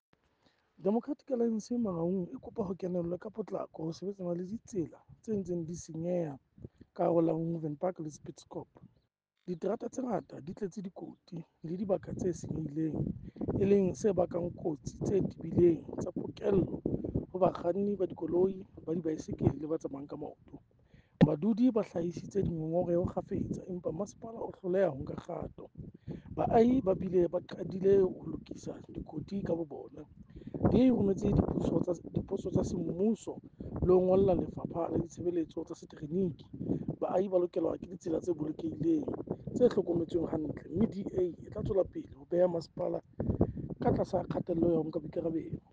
Sesotho soundbite by Cllr Kabelo Moreeng.